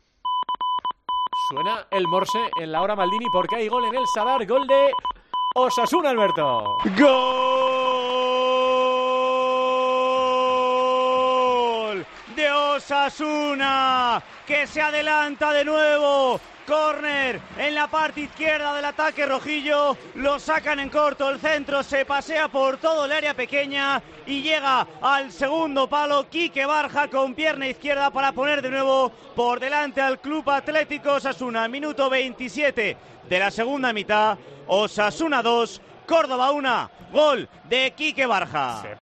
El gol cantado en Tiempo de Juego que salvó una vida